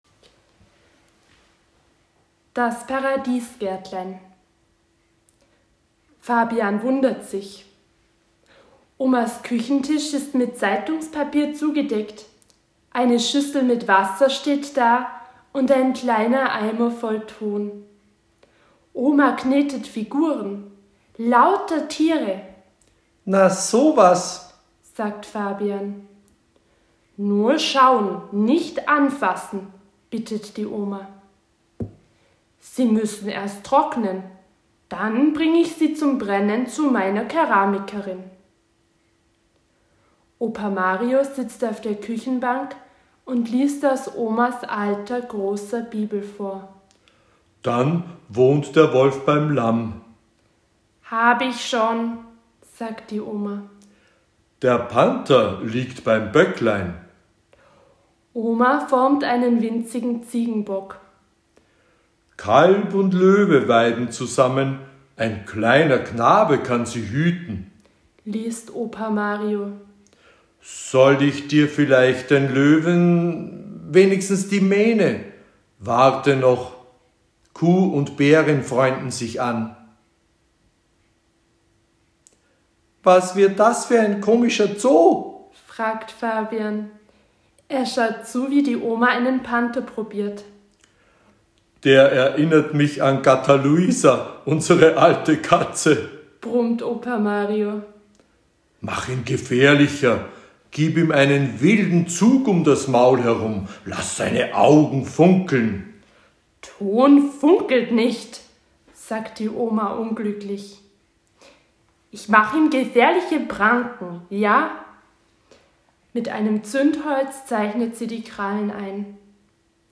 Zuerst haben wir gemeinsam folgende Geschichte von Lene Mayer-Skumanz gehört, die von dem Friedensreich handelt, das mit dem kommenden Messias anbrechen wird (Jes 11):